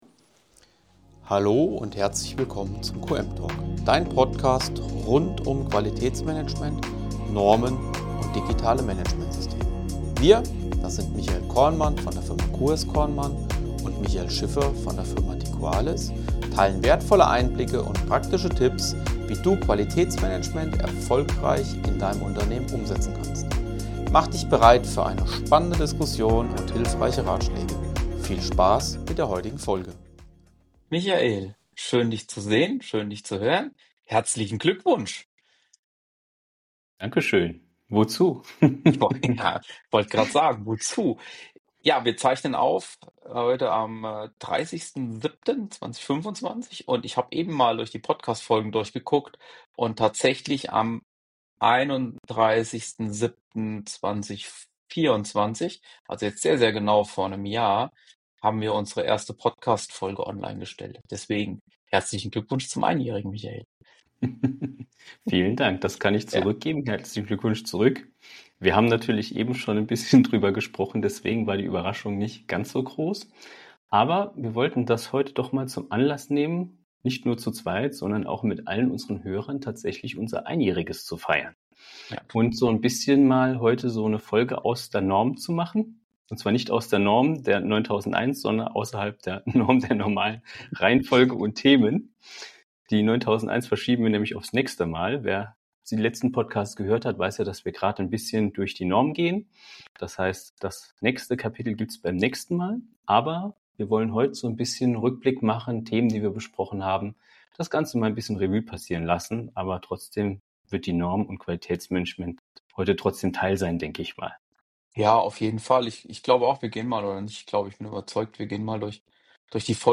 In dieser Jubiläumsfolge wird nicht genormt, nicht auditiert und auch keine Risikoanalyse gemacht – sondern erzählt, gelacht und gedankt.